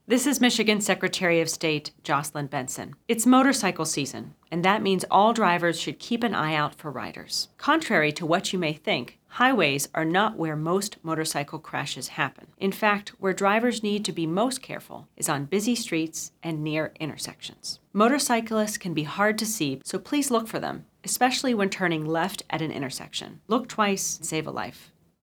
Secretary Benson shares a message to “Look Twice, Save a Life”